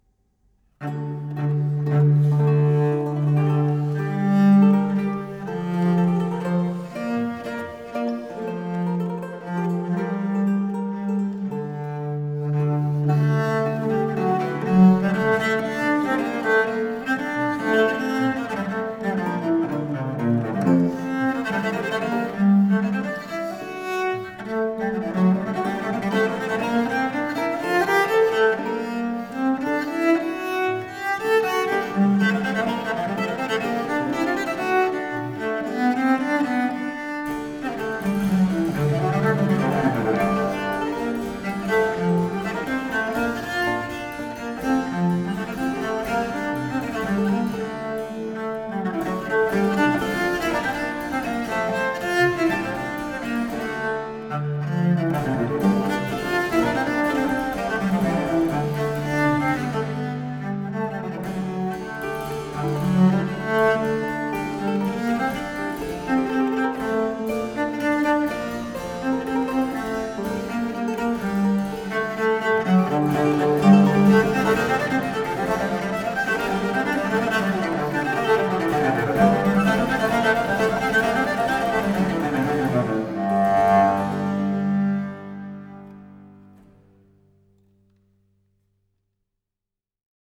Música tradicional
Orquesta